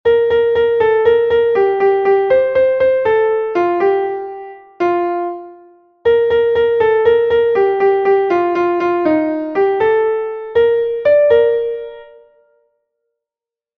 Entoación a capella
Melodía 6/8 en Sib M